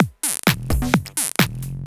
Electrohouse Loop 128 BPM (36).wav